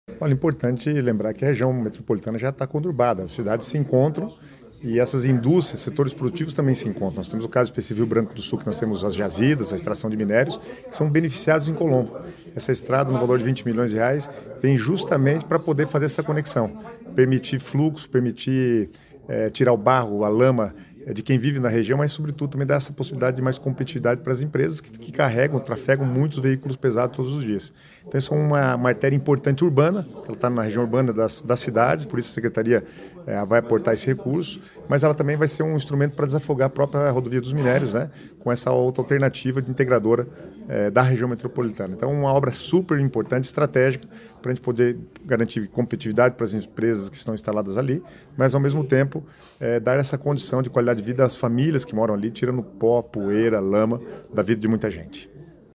Sonora do secretário das Cidades, Guto Silva, sobre a pavimentação de estrada entre Rio Branco do Sul e Colombo